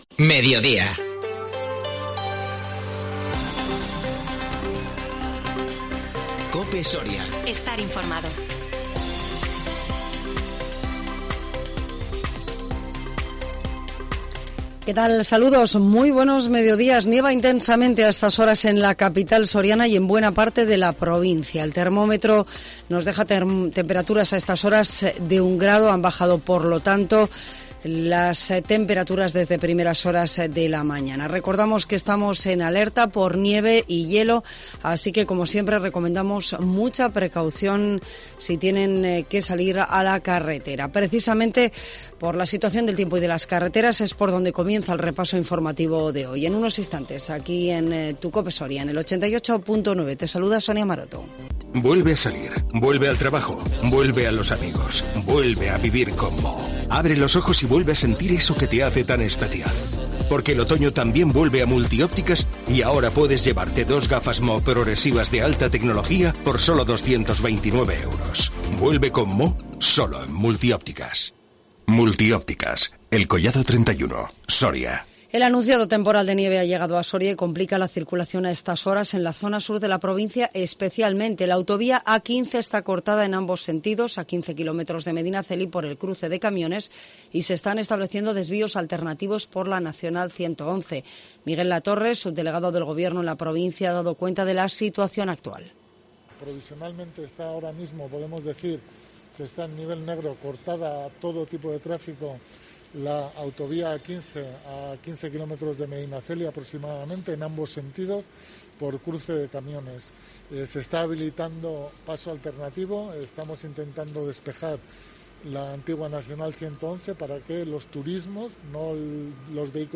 INFORMATIVO MEDIODÍA 23 NOVIEMBRE 2021